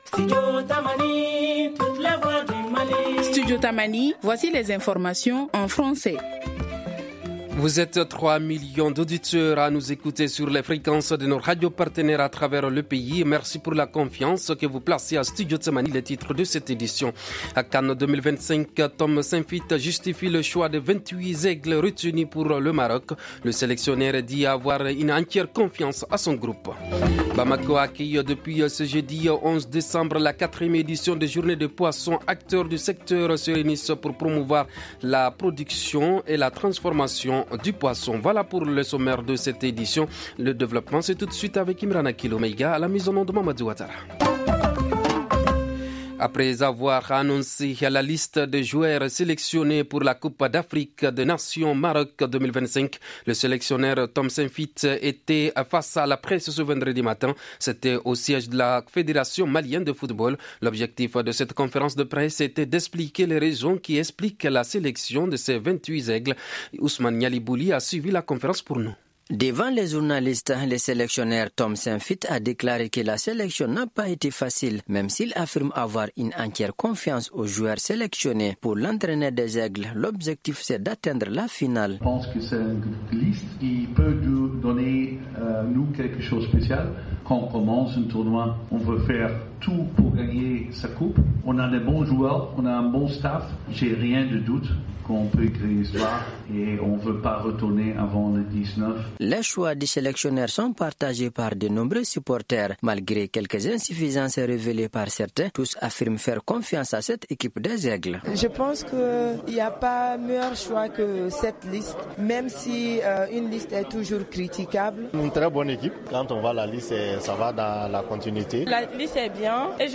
Le journal en Français du 12 décembre 2025